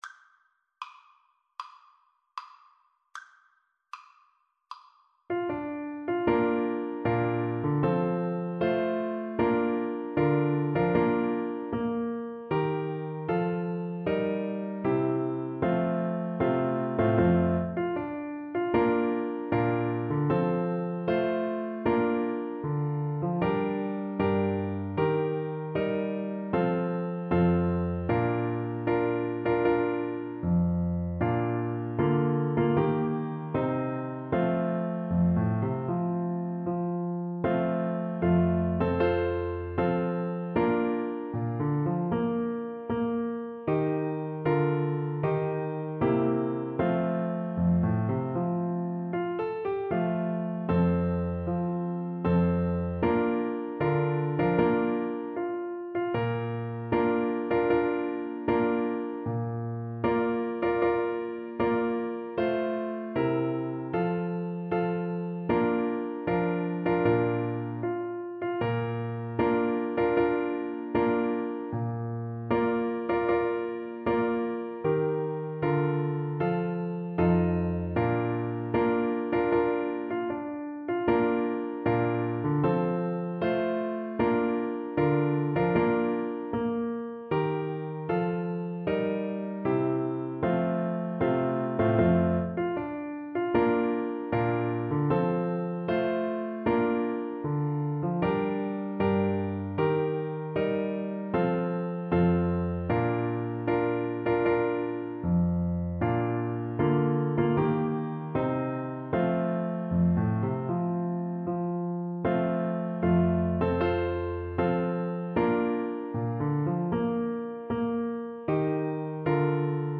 Clarinet
4/4 (View more 4/4 Music)
Bb major (Sounding Pitch) C major (Clarinet in Bb) (View more Bb major Music for Clarinet )
March =c.100
Traditional (View more Traditional Clarinet Music)